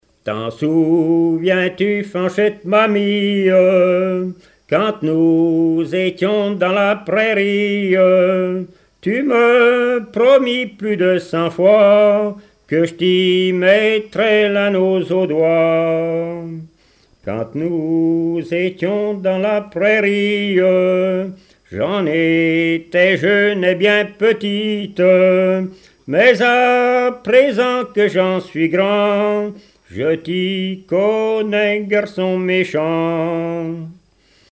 Genre strophique
chansons et témoignages parlés
Pièce musicale inédite